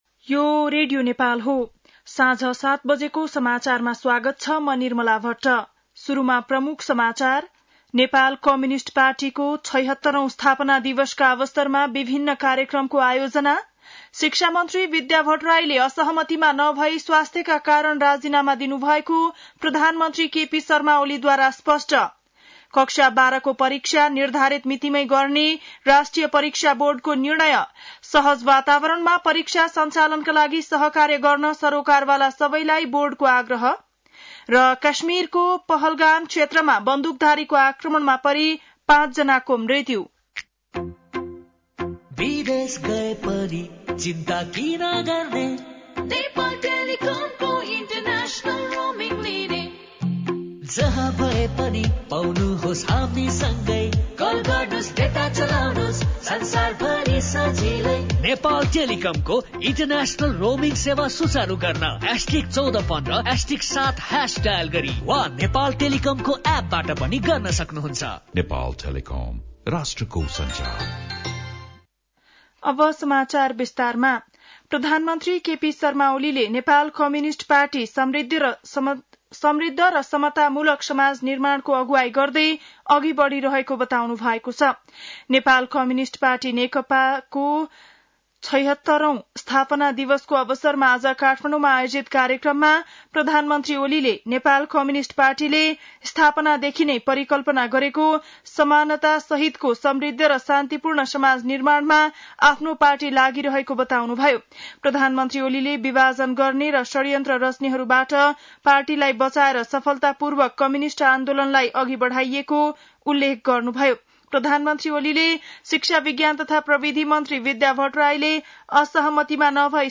बेलुकी ७ बजेको नेपाली समाचार : ९ वैशाख , २०८२
7-pm-nepali-news-2.mp3